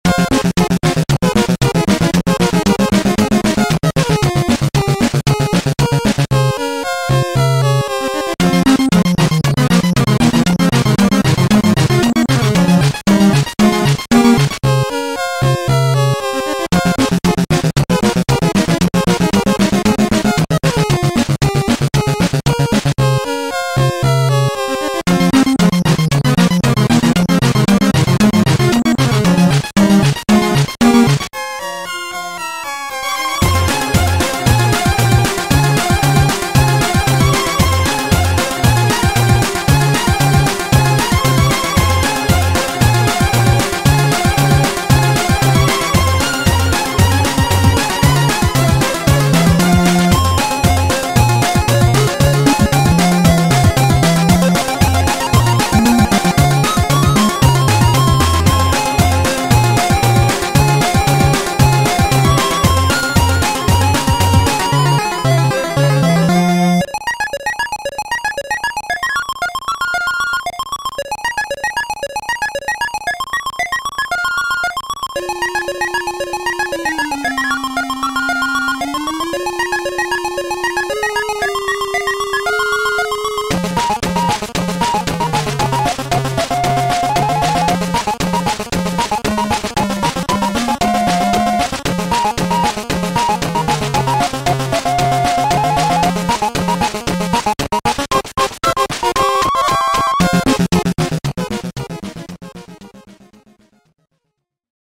BPM230
Audio QualityPerfect (Low Quality)